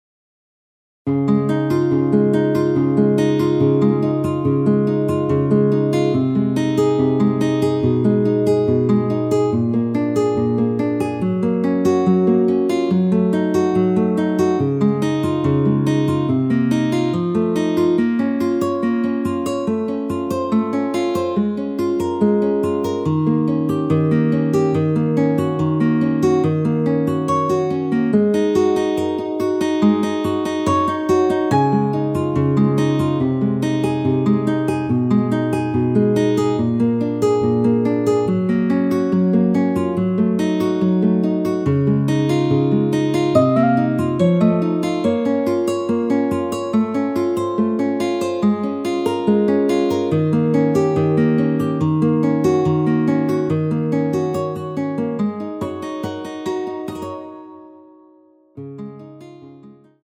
여자키에서 (-1) 내린 MR입니다.
Db
앞부분30초, 뒷부분30초씩 편집해서 올려 드리고 있습니다.
중간에 음이 끈어지고 다시 나오는 이유는